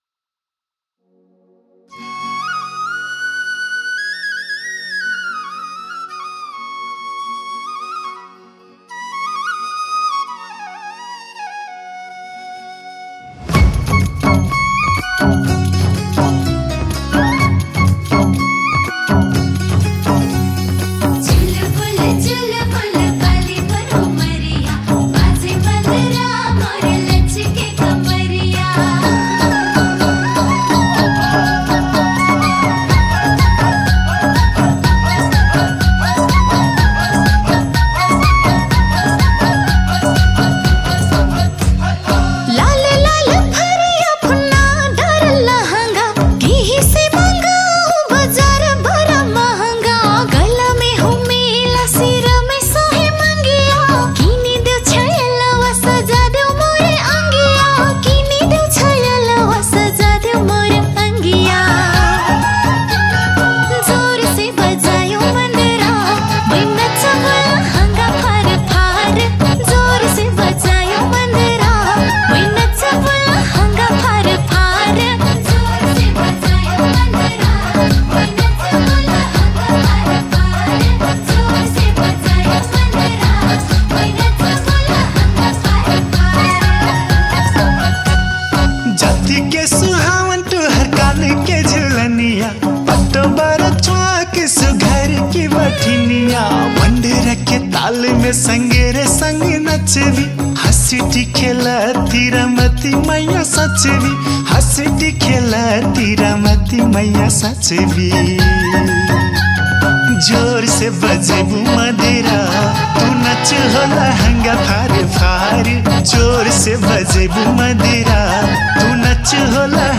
Tharu Culture Song